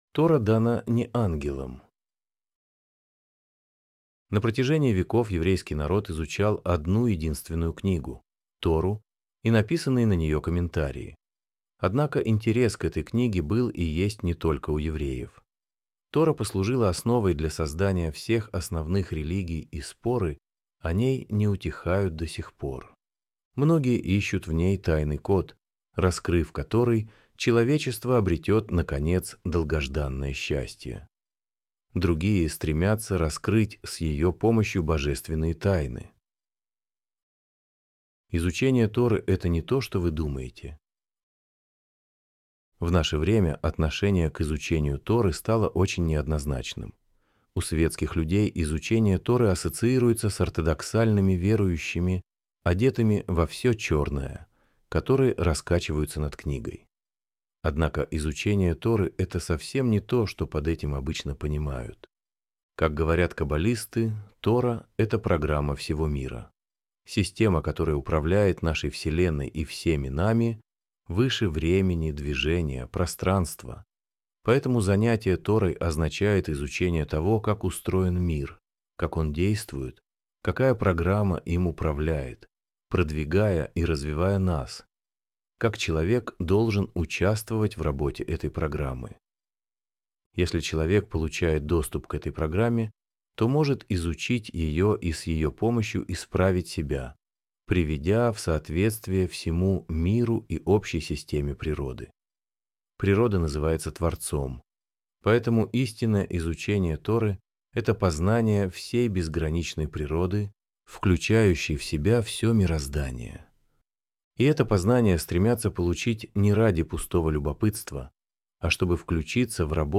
Аудиоверсия статьи